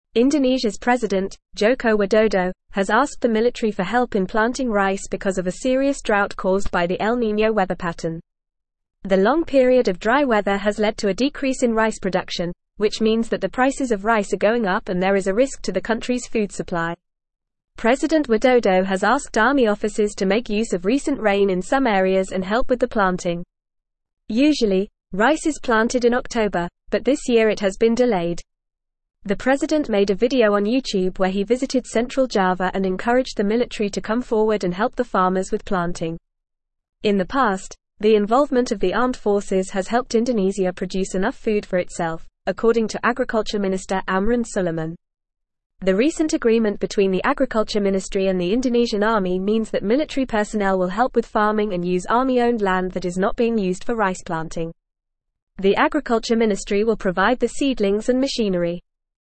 Fast
English-Newsroom-Upper-Intermediate-FAST-Reading-Indonesias-President-Calls-on-Military-to-Assist-Farmers.mp3